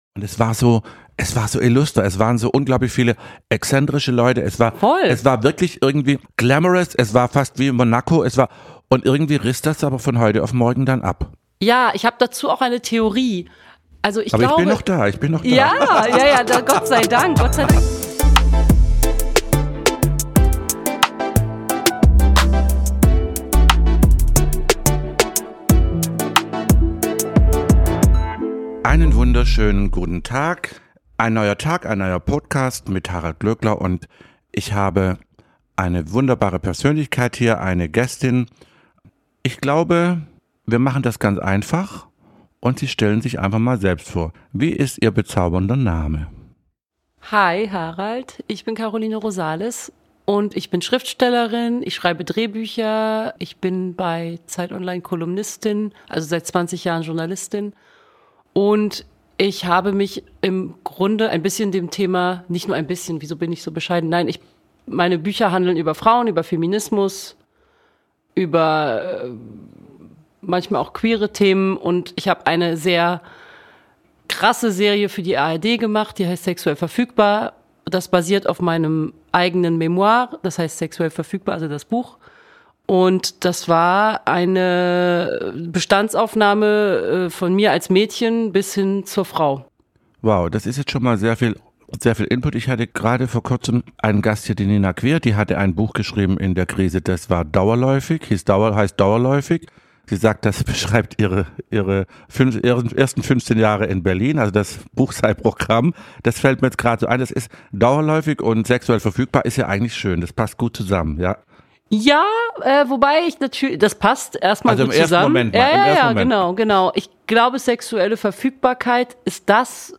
Harald Glööckler eröffnet das Gespräch mit seiner Bewunderung für die Rolle der Frau und setzt sich mit dem Thema Feminismus auseinander.